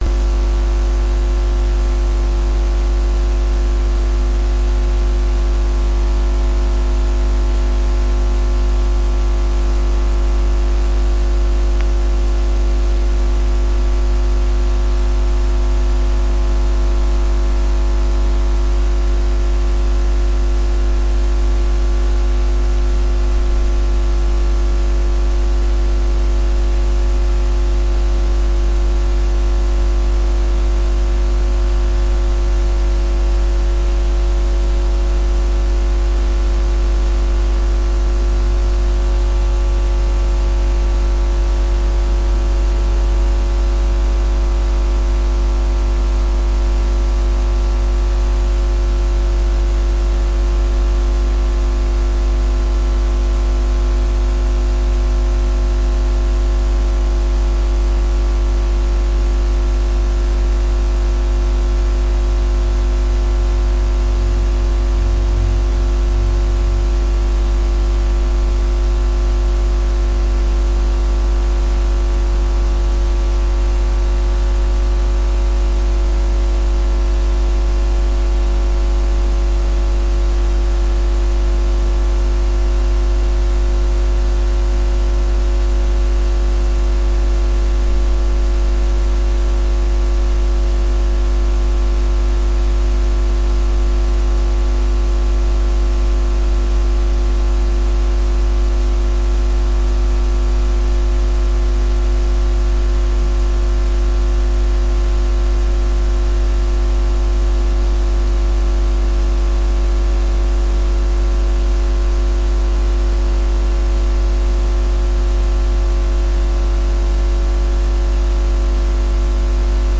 描述：助推器驾驶检查器
Tag: 起动器 ECU 发动机